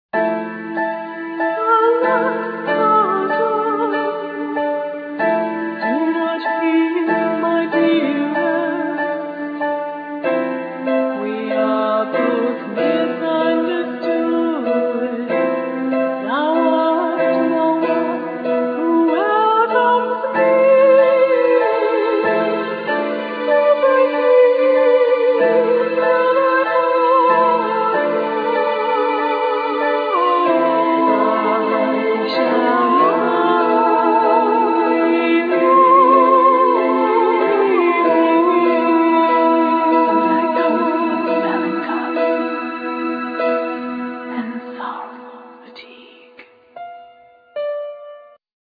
All instruments,Voice
Voice